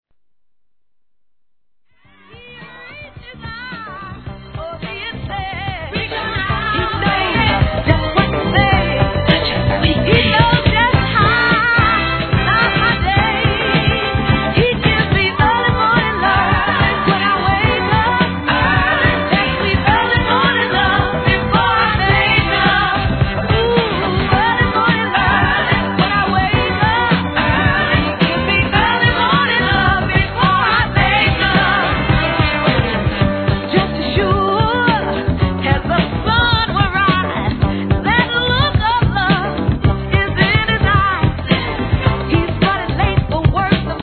HIP HOP/R&B
大好評！！オトナなパーティーを彩るダンクラ、フリーソウルがギッシリ！！